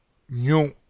The letter Ñ, is one of “the new letters” for the English-Speaking people and you can try the phonetic association, which is very nasalized,  with sound “ni” in Onion.